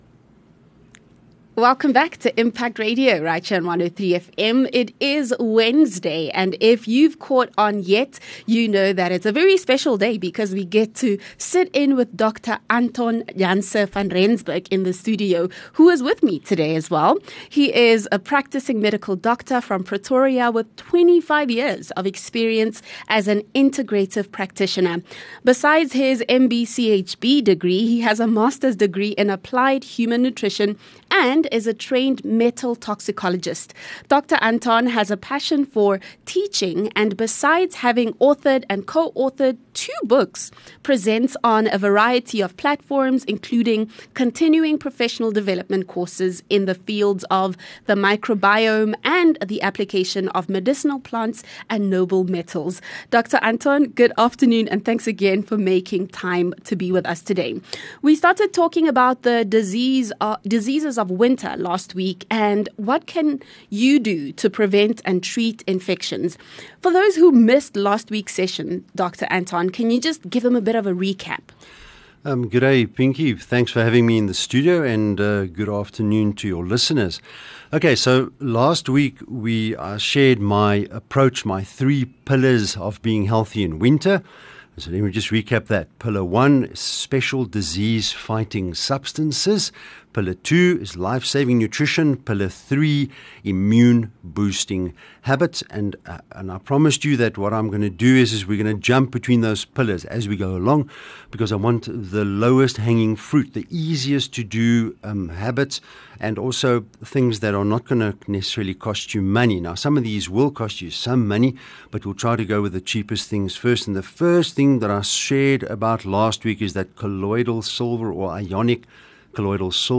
View Promo Continue Install ON-AIR CONTENT 30 Jul SilverLab Healthcare Feature